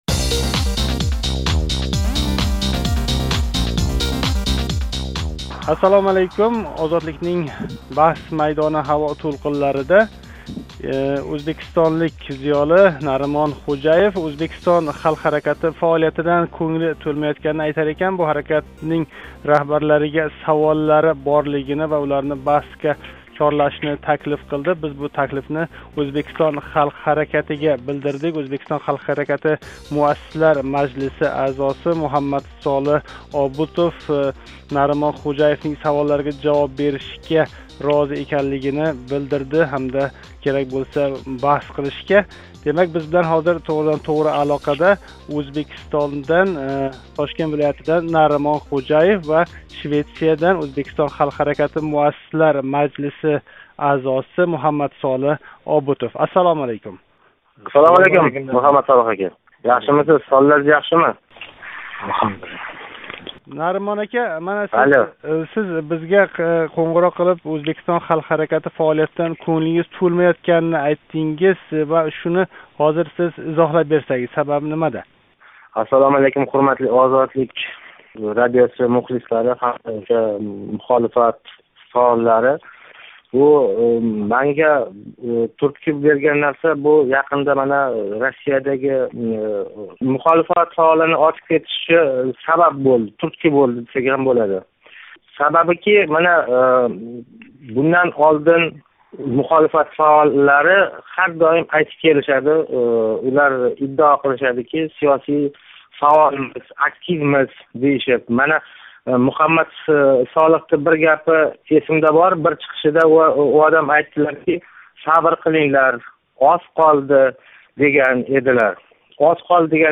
Озодлик мухлиси билан ЎХҲ фаоли ана шу масала устида баҳслашди.